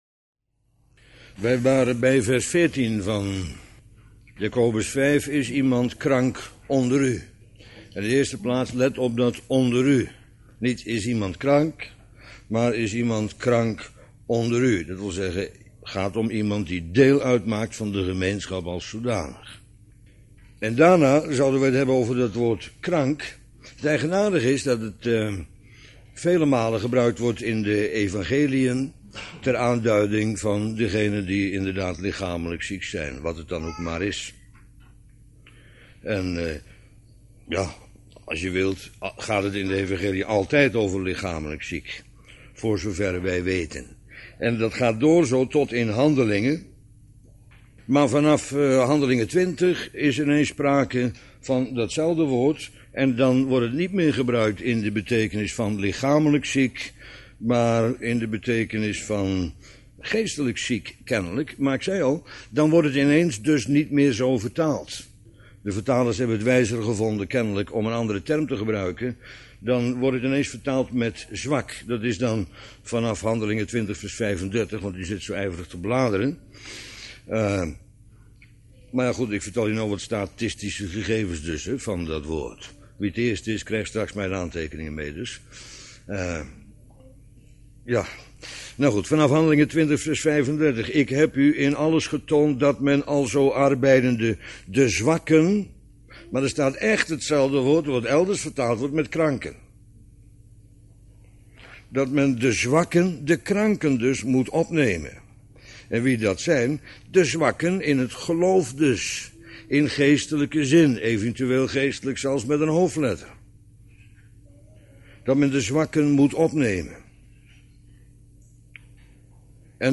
Bijbelstudie lezingen op mp3.